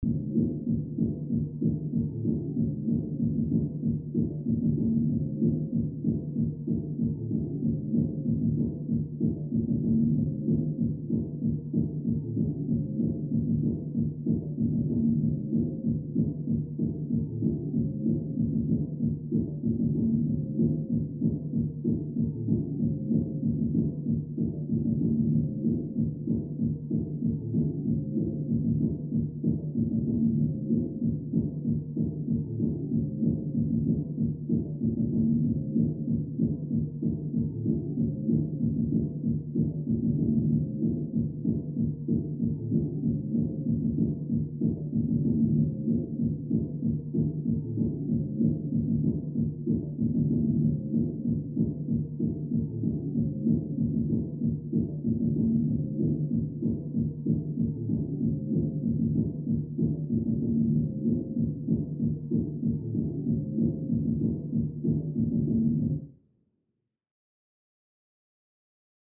Music; Electronic Dance Beat, From Down Hallway.